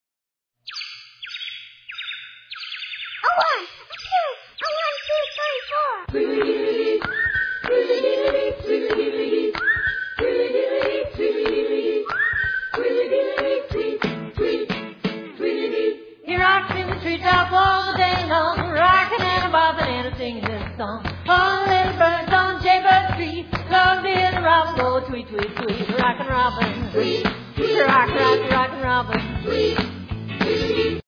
excellent rock 'n' roll with wacky skits